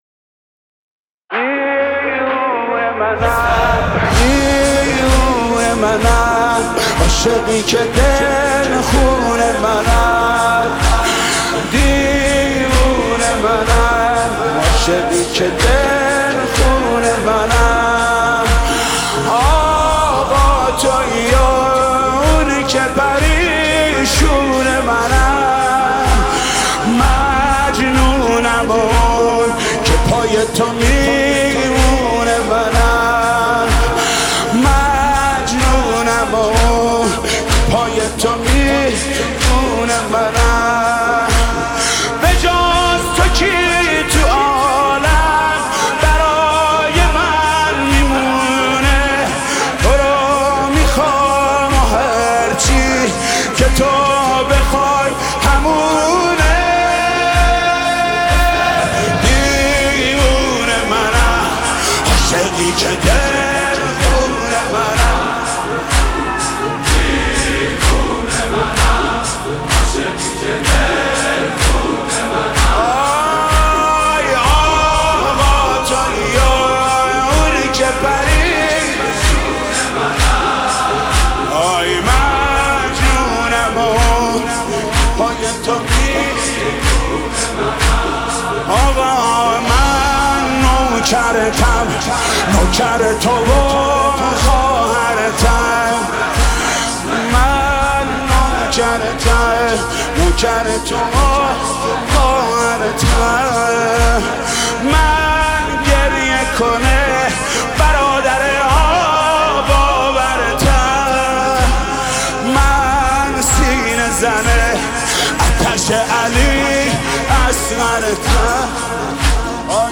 مداح اهل بیت ، محرم الحرام